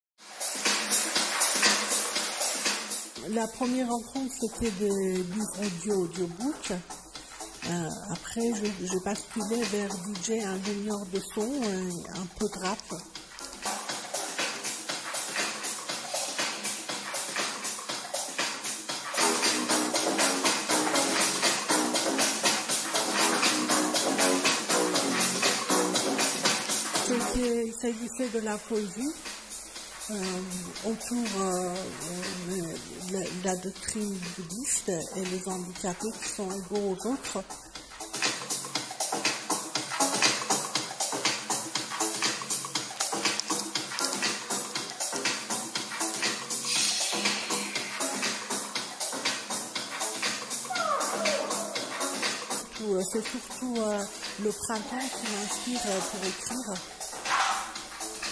Replay 21/06 - Cinaps TV Interview